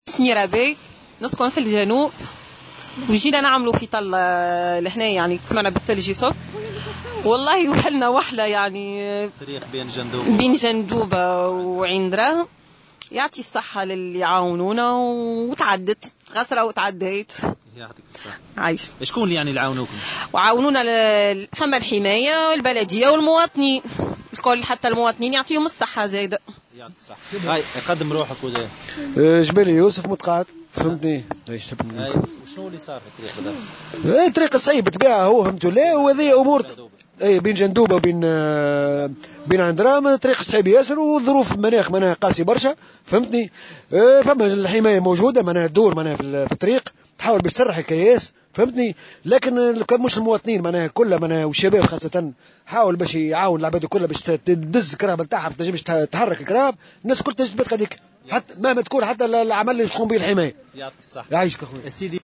Reportage t√©l√©charger partager sur